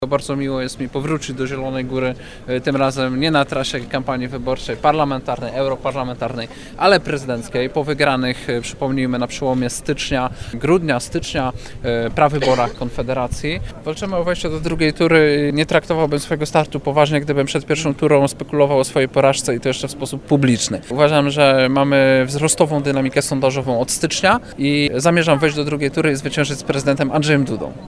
W ramach kampanii prezydenckiej do Zielonej Góry przyjechał w niedzielę Krzysztof Bosak. Kandydat Konfederacji spotkał się na deptaku ze swoimi sympatykami.
Na spotkanie przyszło ponad 200 osób. Krzysztof Bosak pytany był głównie o sprawy gospodarcze, o to jak widzi swoją prezydenturę i jak ocenia swoje szanse w wyścigu o pałac prezydencki.